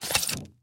Звуки пенопласта
один кусок пенопласта коснулся другого